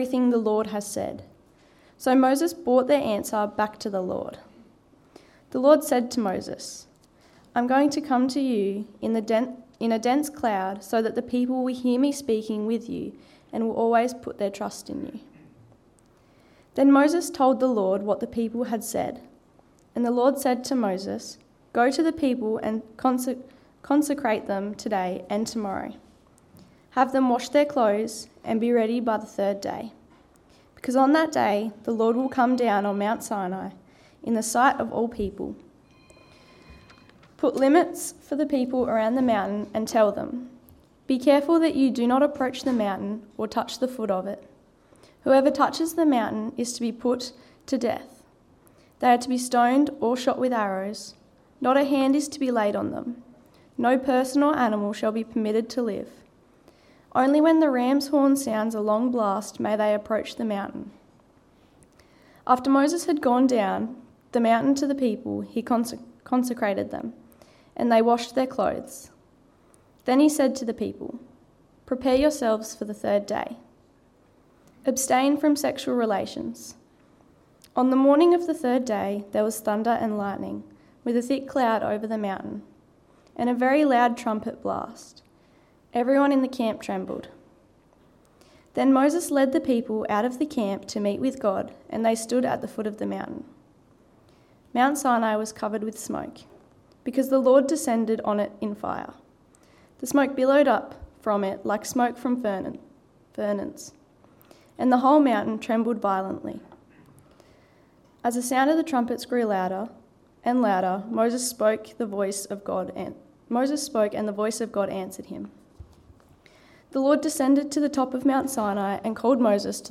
Text: Exodus 19: 7-25 Sermon